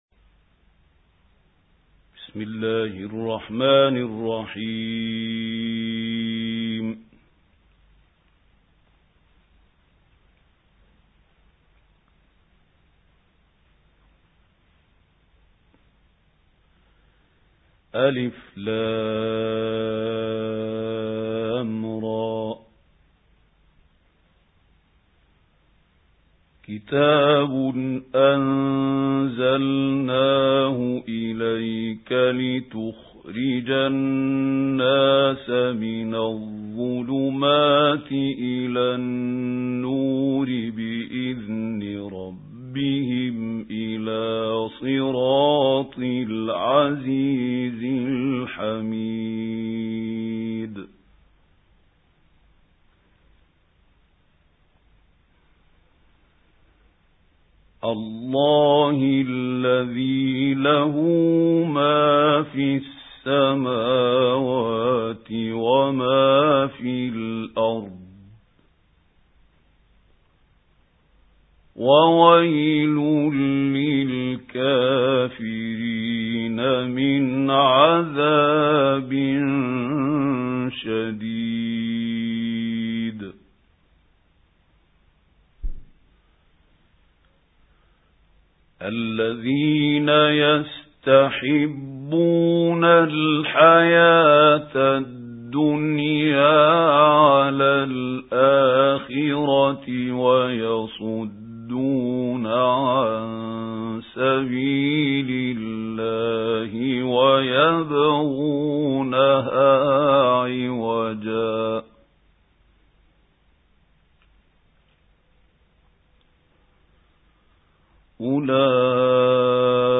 سُورَةُ ابراهيم بصوت الشيخ محمود خليل الحصري